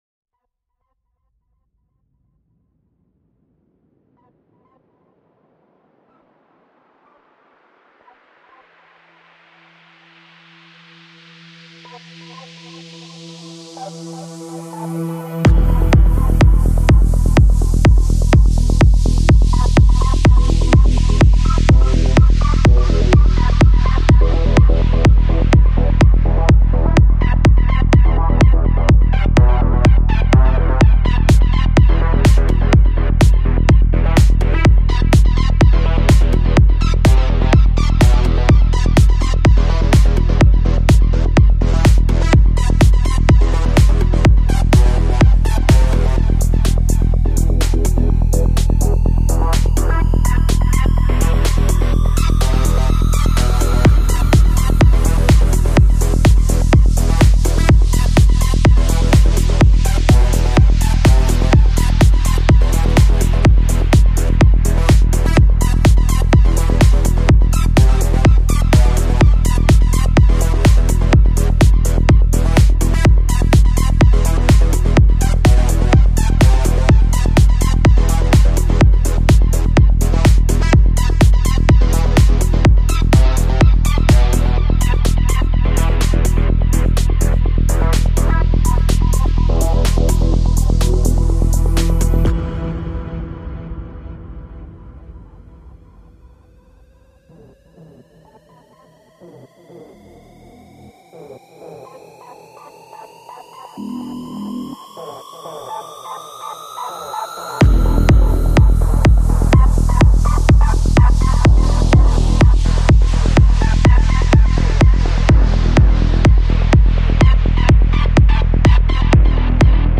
Industrial music : GRIME mp3 format.